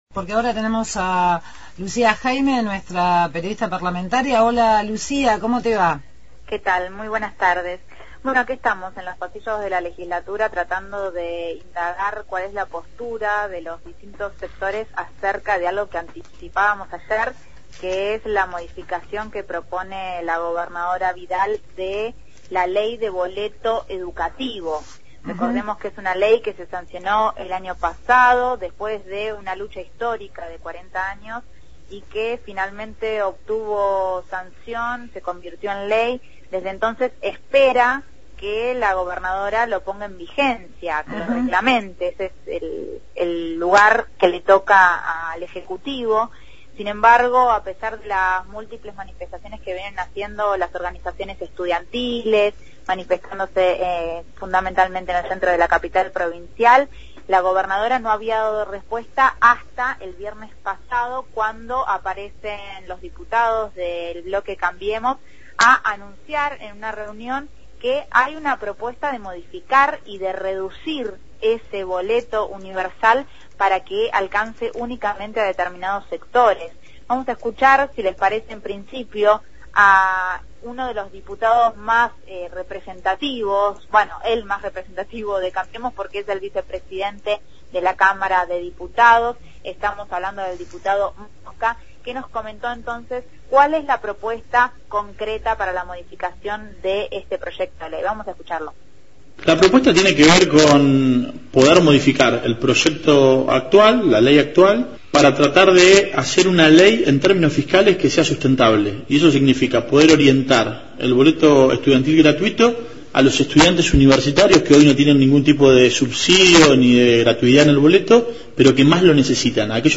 Informe/ Diputados sobre boleto gratuito – Radio Universidad
desde la Legislatura con diputados por el pedido del boleto educativo.